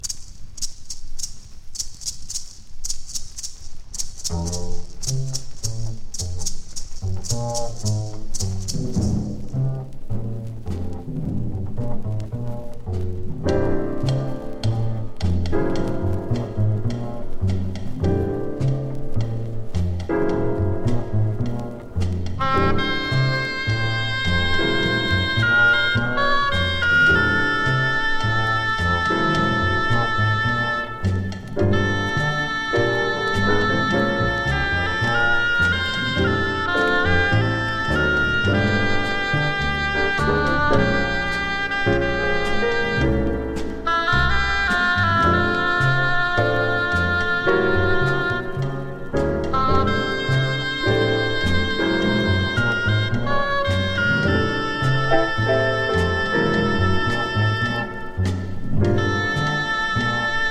マルチリード奏者’60年作。